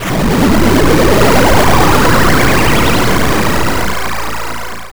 bfxr_BombOld.wav